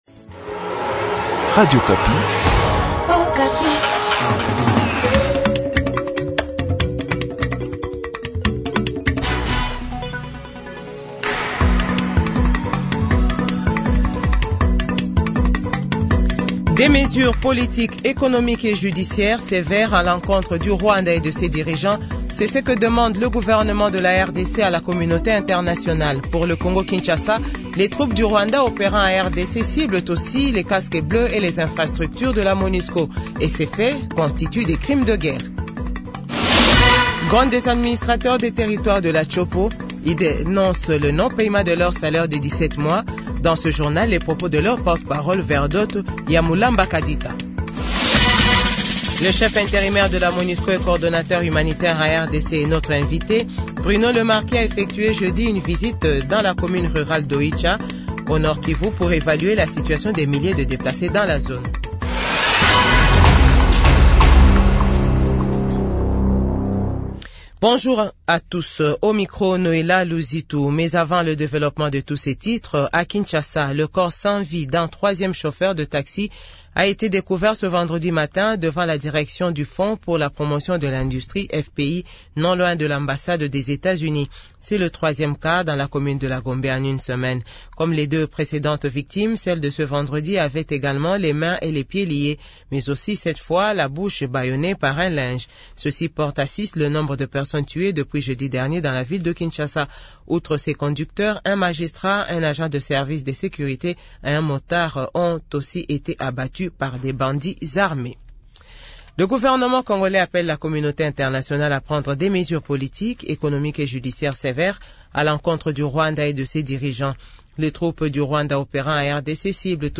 JOURNAL FRANCAIS 12H00 du VENDREDI 12 JUILLET 2024